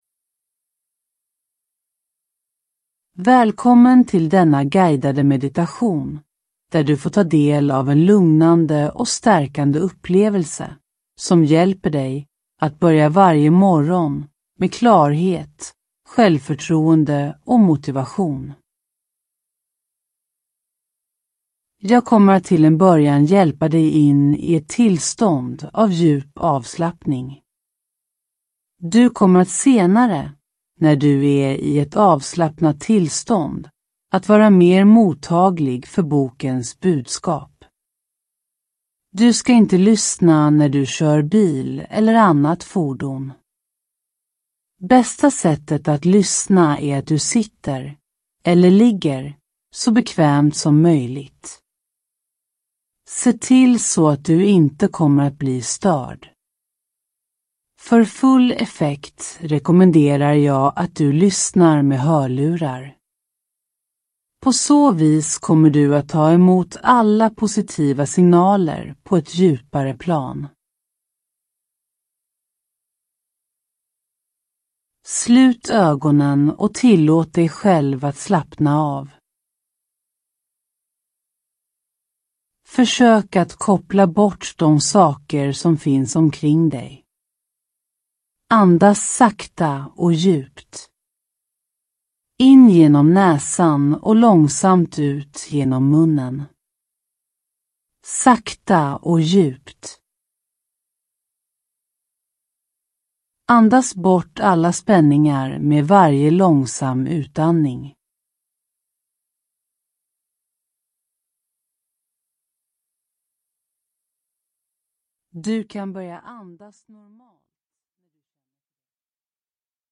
Ljudbok
I denna guidade meditation tar du del av en lugnande och stärkande upplevelse som hjälper dig att börja varje morgon med klarhet, självförtroende och motivation.
Med mjuka instruktioner och positiv vägledning leds du in i ett tillstånd av djup avslappning och själsligt lugn. Genom visualiseringar, affirmationer och enkla tekniker för självkärlek och tacksamhet skapas en inre balans som ger dig energi och styrka för dagens utmaningar.